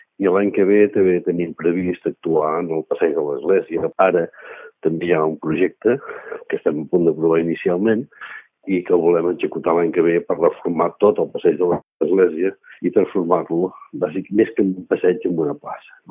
Entrevista al regidor d’urbanisme, Jordi Colomí